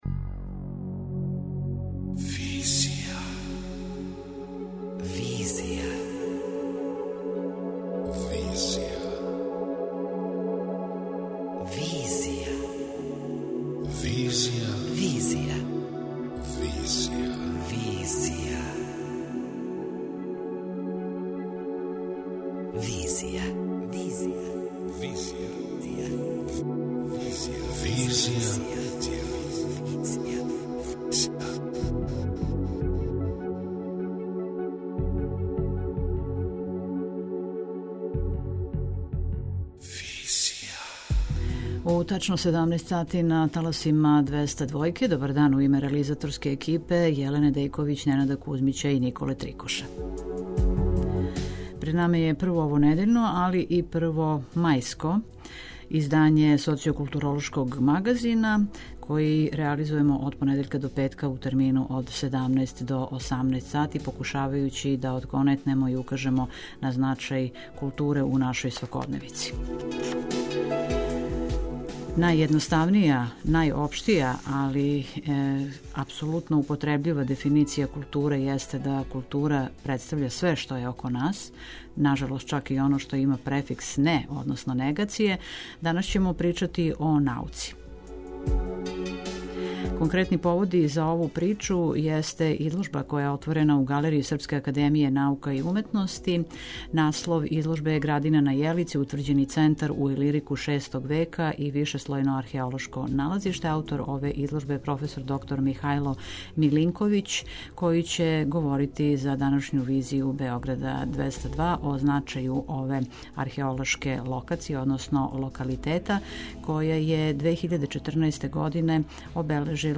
преузми : 27.25 MB Визија Autor: Београд 202 Социо-културолошки магазин, који прати савремене друштвене феномене.